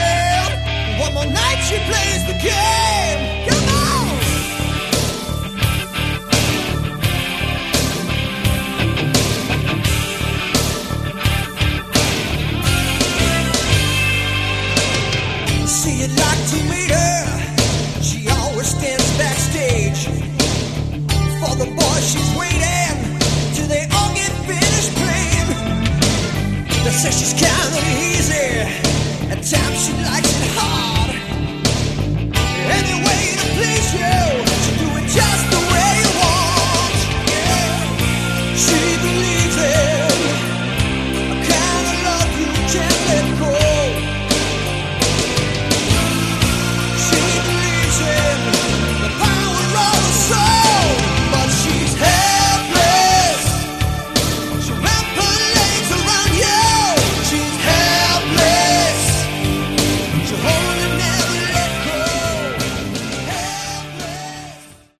Category: AOR
lead and backing vocals
guitars, bass, backing vocals
keyboards, drums, percussion, backing vocals